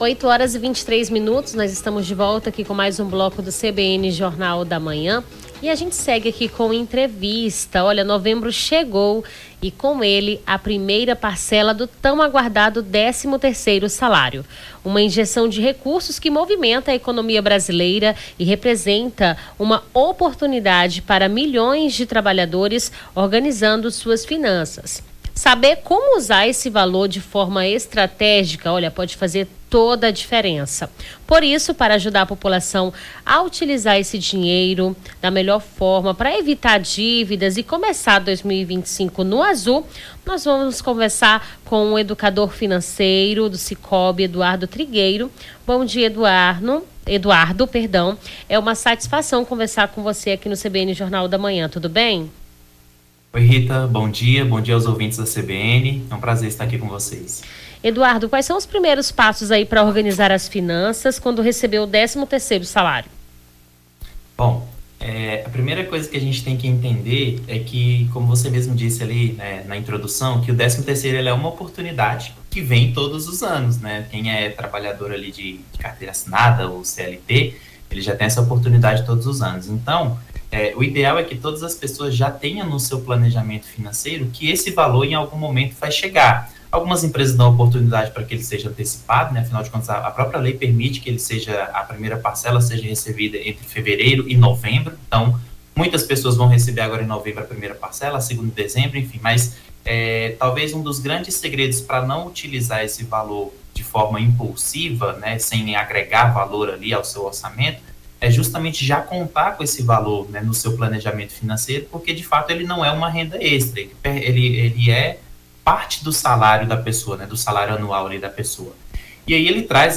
Nome do Artista - CENSURA- ENTREVISTA COMO ORGANIZAR AS FINANÇAS (13-11-24).mp3